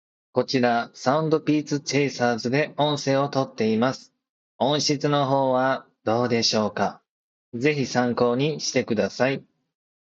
多少こもった感じは間違いなくあります。
相手側にもこもった感はあるとのことです。
ただ通話に問題を感じることはありません。
せっかくなのでマイク音質をどうぞ！